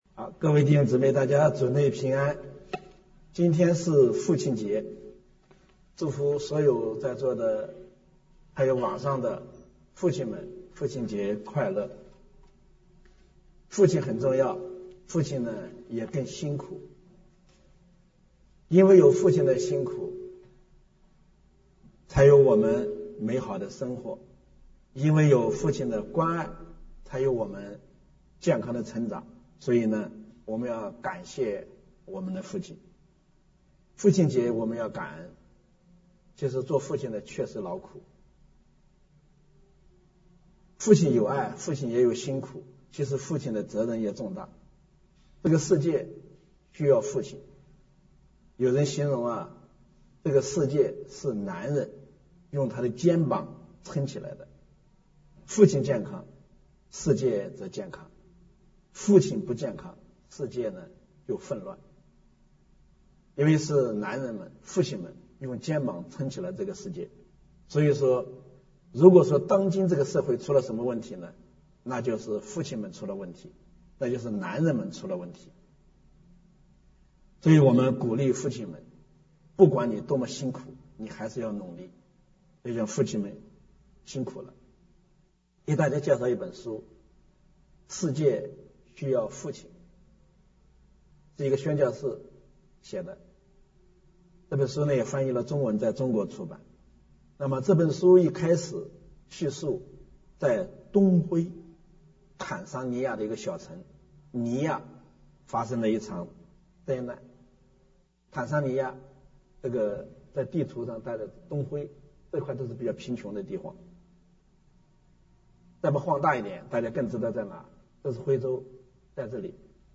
6/19/2022 国语崇拜講題: 「为父的心」